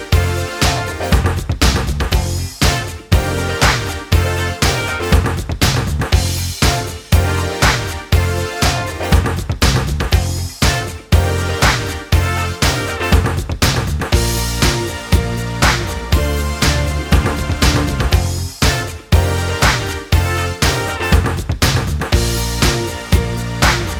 Minus Guitar Pop (1980s) 3:38 Buy £1.50